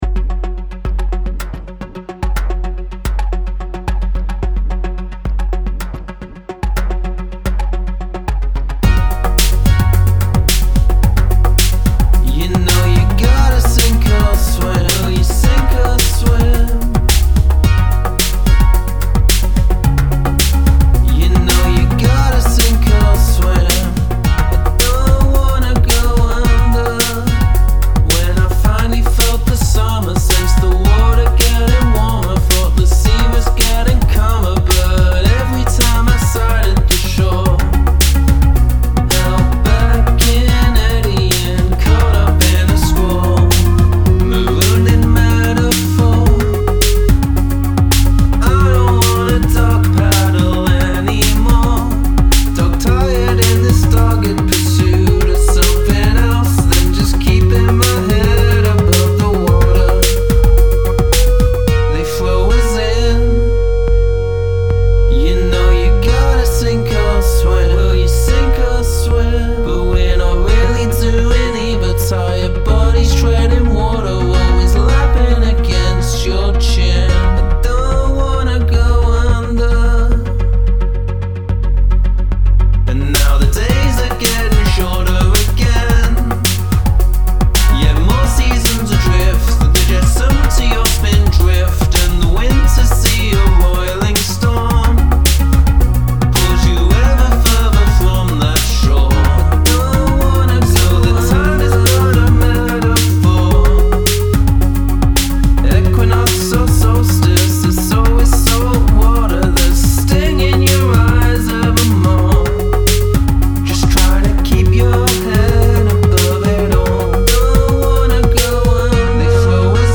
Short intro.